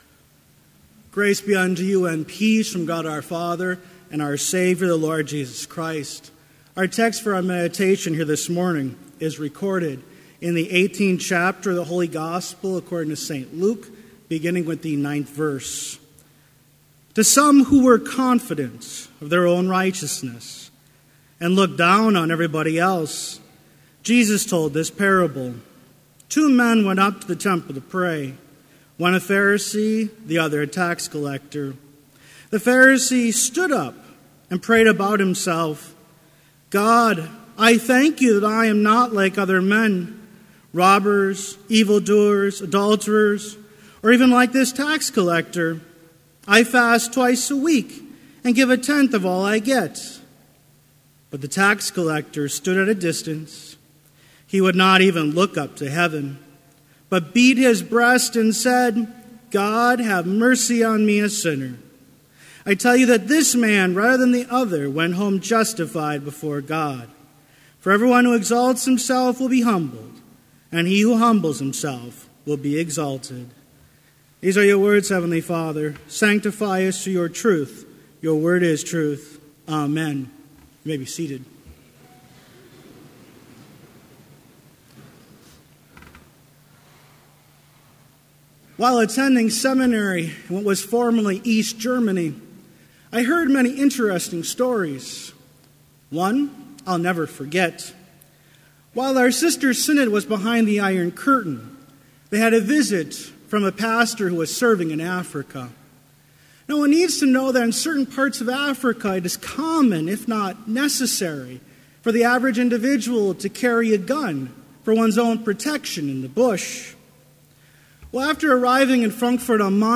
Complete service audio for Chapel - October 24, 2016
Order of Service Prelude Hymn 227, vv. 1 – 3, Salvation Unto Us Is Come Reading: Luke 18:10-14 Devotion Prayer Hymn 227, vv. 5 – 7, Still all the law… Blessing Postlude